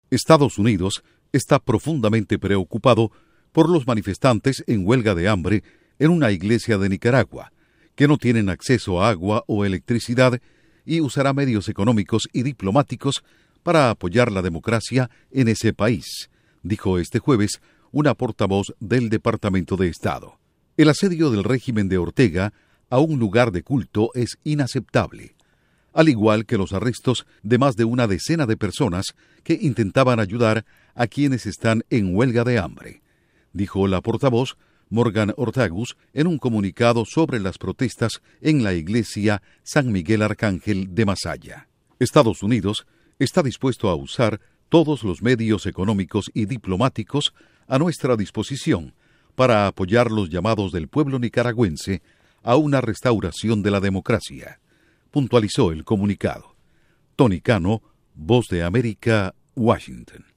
Departamento de Estado califica asedio del gobierno Nicaragua a manifestantes en iglesia como "inaceptable”. Informa desde la Voz de América en Washington